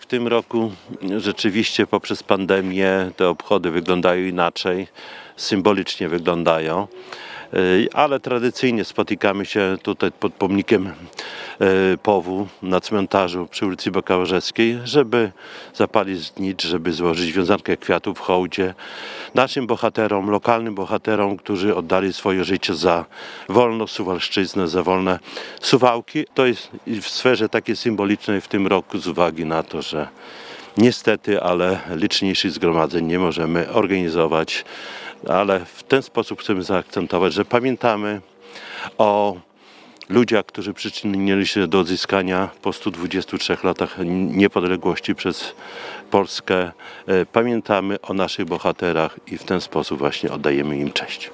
– Oficjalne obchody 102. rocznicy odzyskania Niepodległości są w tym roku skromniejsze, bo obostrzenia związane z koronawirusem obowiązują wszystkich – mówi Czesław Renkiewicz, prezydent Suwałk.